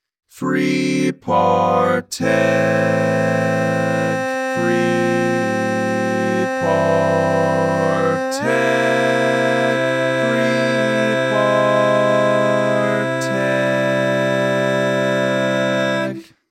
Key written in: C Major
How many parts: 3
Type: Other male
All Parts mix: